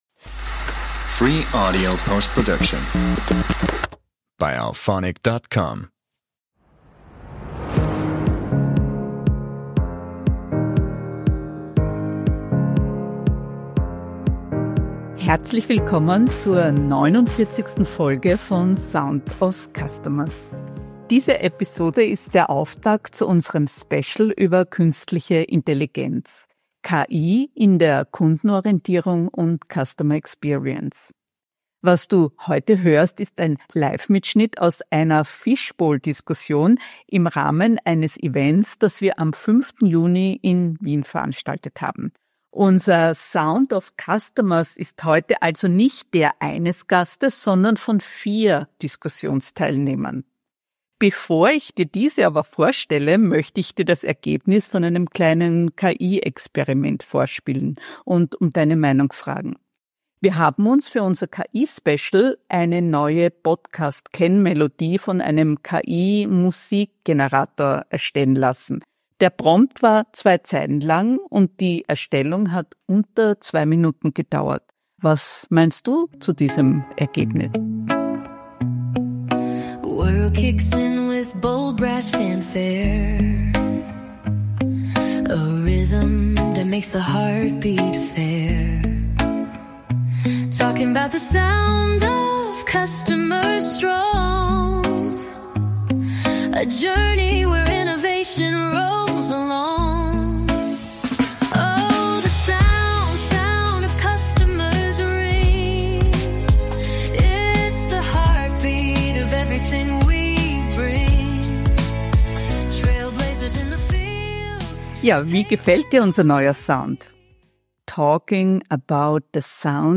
Kundenzentrierte Leader im Fishbowl 1 (Live-Mitschnitt): Wie Führungsarbeit mit KI gelingt ~ Sound of Customers Podcast